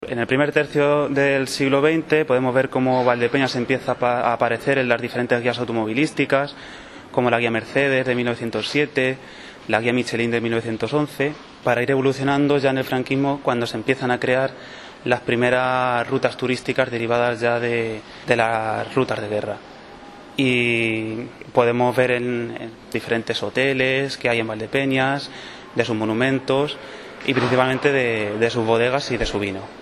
La ponencia “¿Turismo en la Mancha? Valdepeñas en los itinerarios turísticos del franquismo” cerró ayer el XI Ciclo de Conferencias “Valdepeñas y su Historia”, una iniciativa del Consistorio que se celebra con carácter anual y que pretende aportar datos de las nuevas investigaciones que se están realizando y se pueda arrojar luz sobre periodos que se desconocen de la historia local.